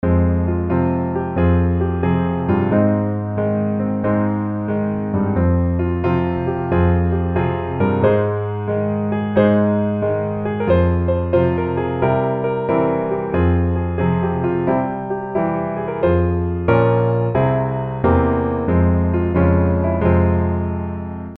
F Minor